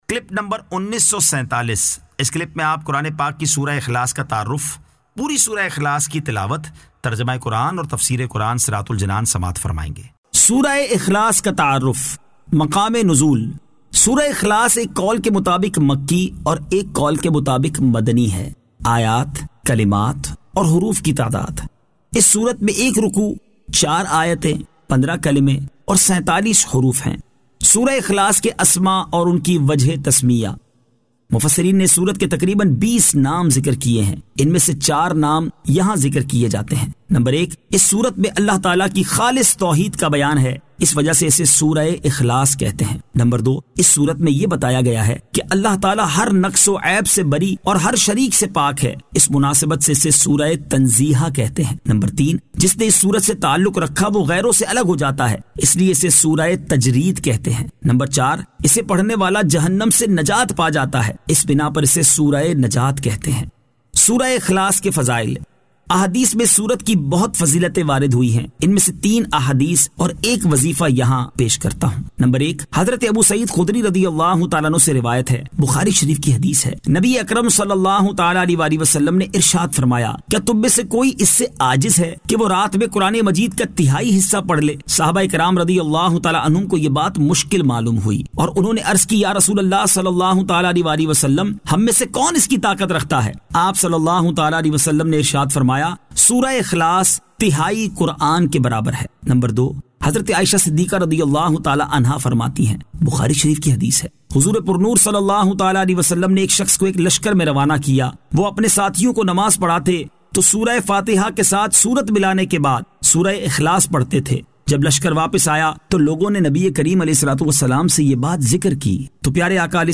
Surah Al-Ikhlas 01 To 04 Tilawat , Tarjama , Tafseer